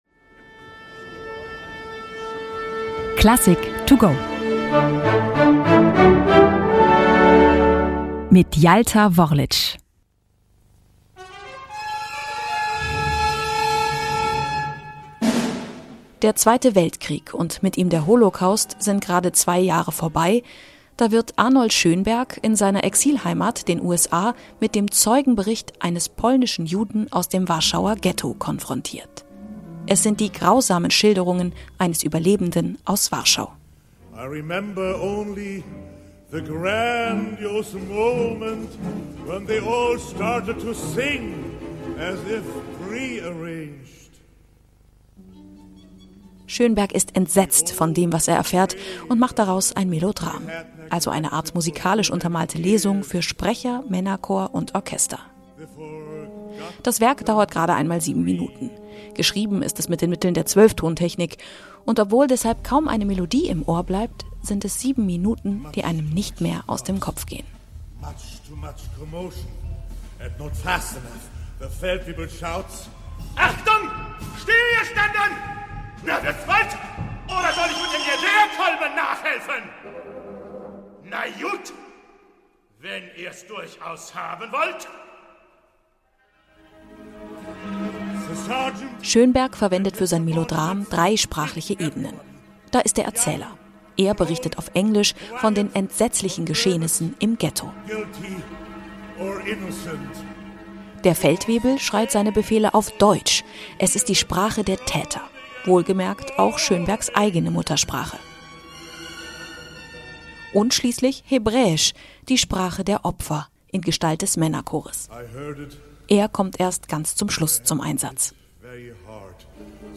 der digitalen Werkeinführung für unterwegs.